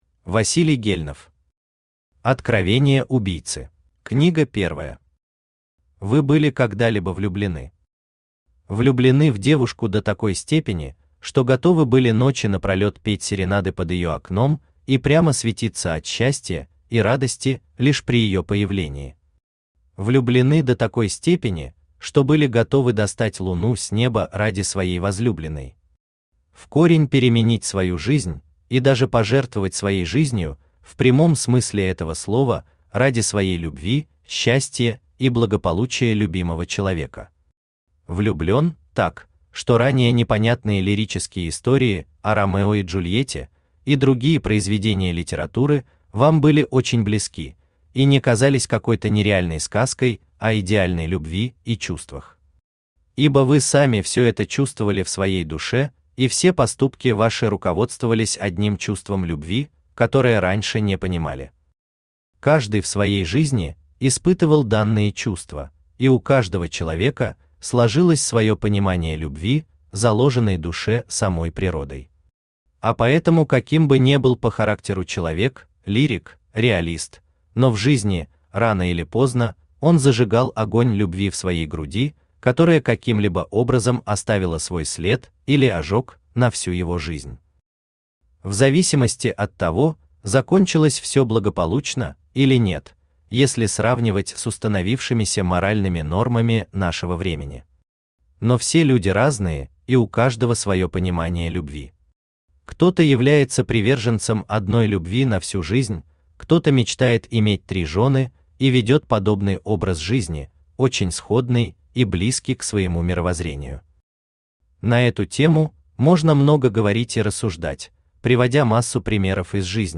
Аудиокнига Откровения убийцы | Библиотека аудиокниг
Aудиокнига Откровения убийцы Автор Василий Валерьевич Гельнов Читает аудиокнигу Авточтец ЛитРес.